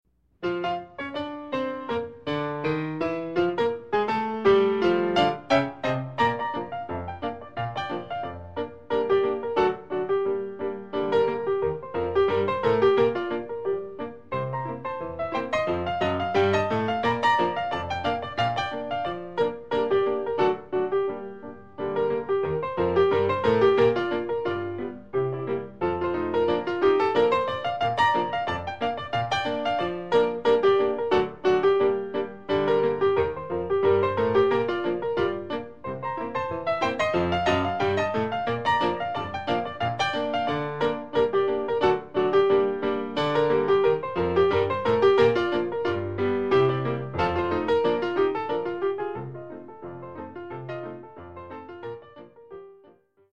jazz.mp3